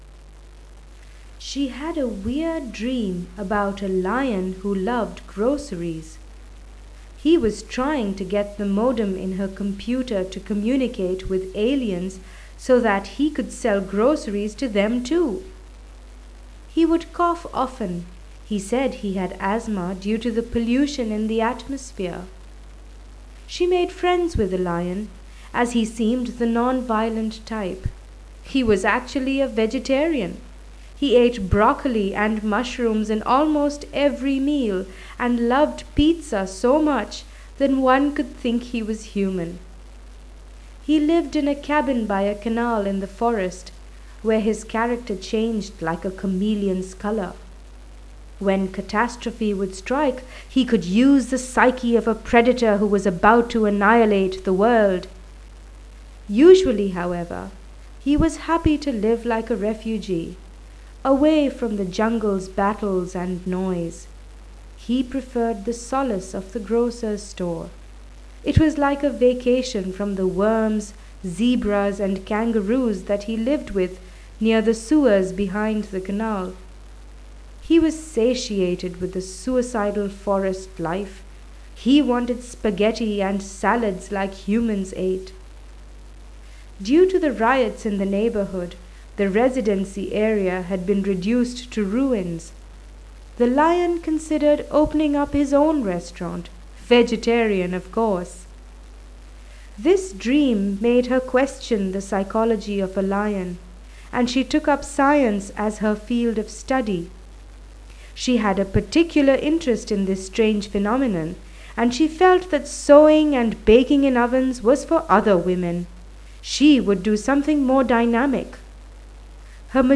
Pronunciation Reading Passage
pronunciation reading passage.wav